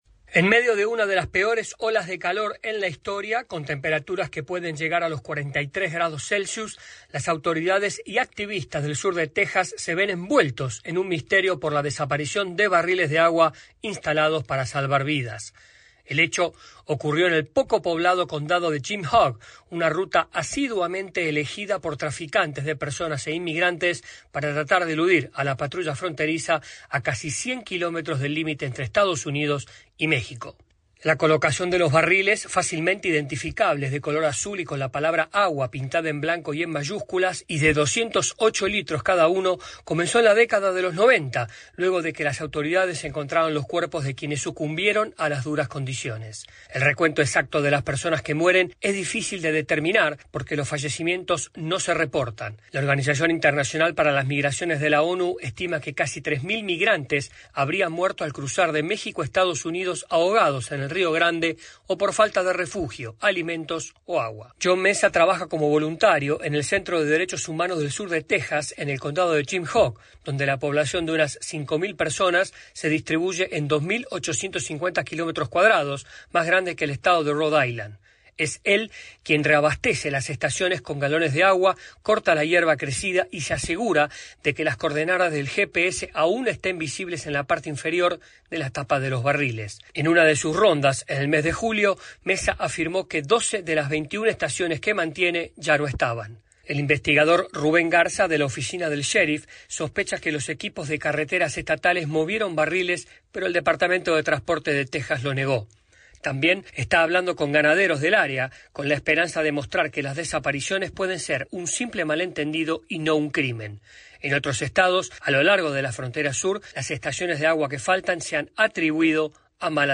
desde la Voz de América en Washington DC